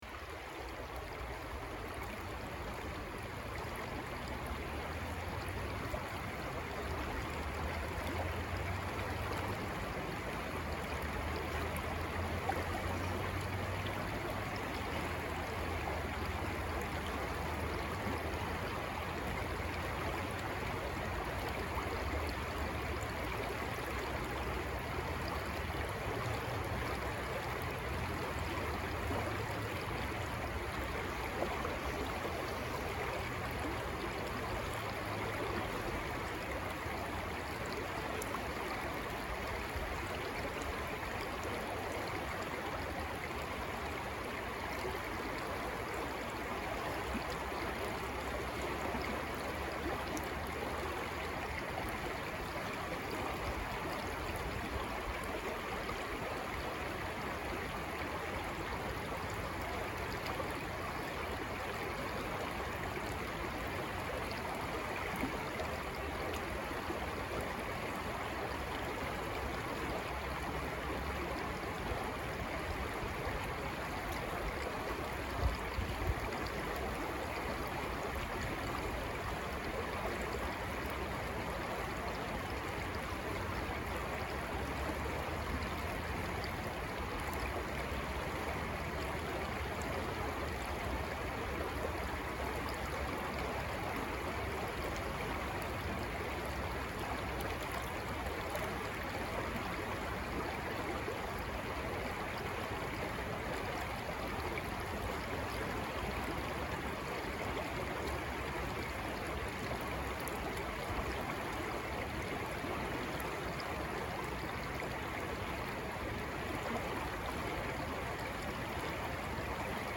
Быстро текущая река
reka-2.mp3